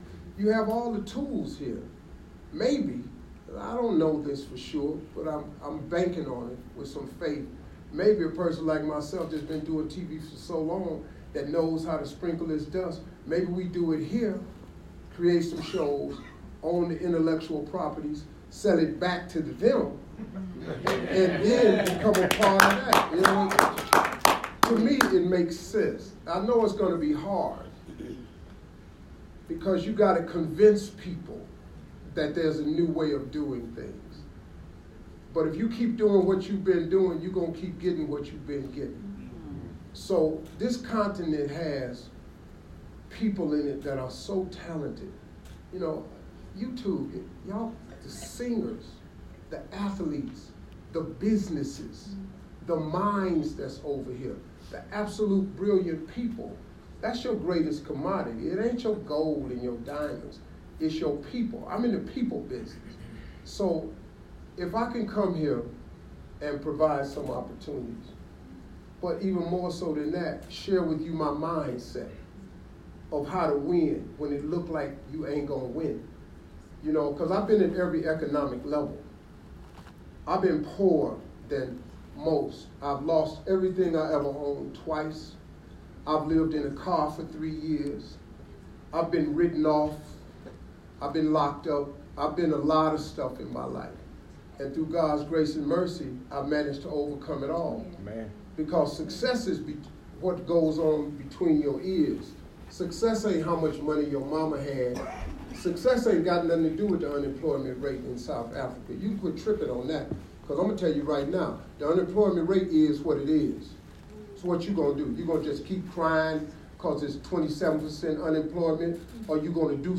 Yesterday I attended the media launch of Family Feud Africa, where Steve Harvey, the Emmy® Award-winning entertainer, radio personality, motivational speaker, New York Times best-selling author, businessman and philanthropist, announced that he would also be hosting the local versions for South Africa and Ghana.